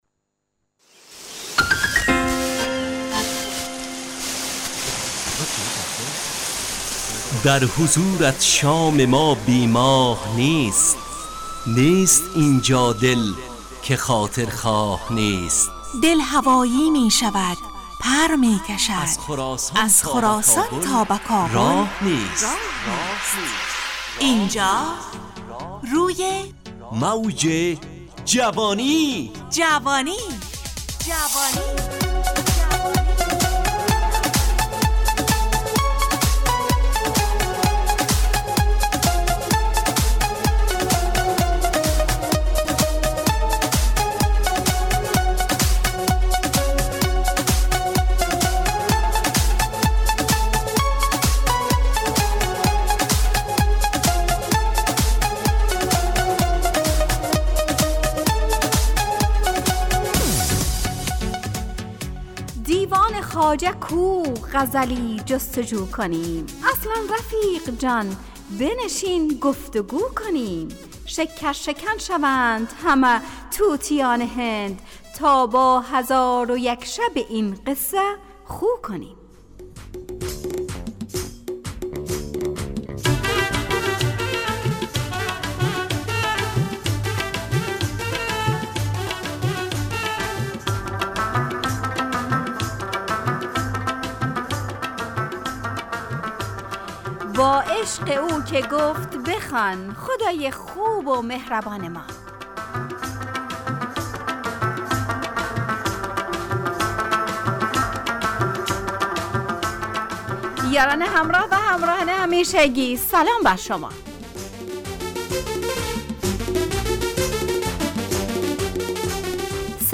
همراه با ترانه و موسیقی مدت برنامه 55 دقیقه . بحث محوری این هفته (اگر کتاب نخوانیم ...)